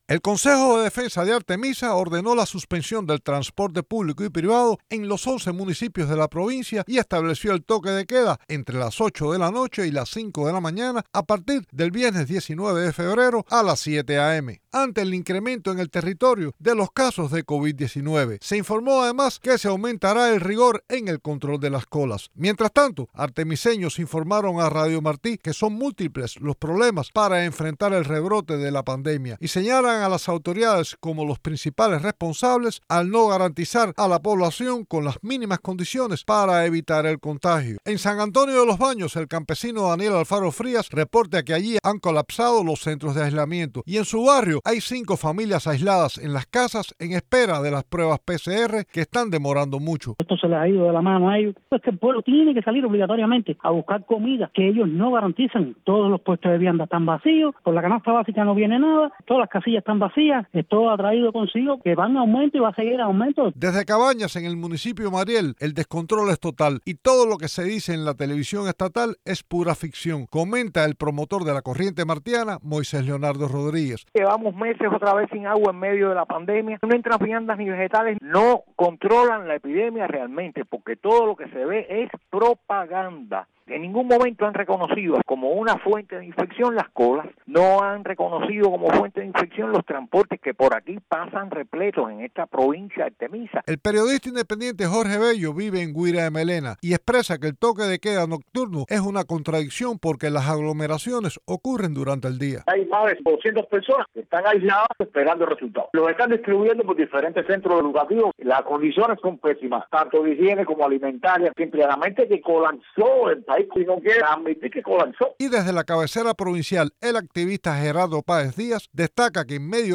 Noticias de Radio Martí
Artemiseños entrevistados por Radio Televisión Martí señalaron a las autoridades como los principales responsables del rebrote de coronavirus que afecta a la provincia, al no garantizar las mínimas condiciones necesarias para evitar el contagio.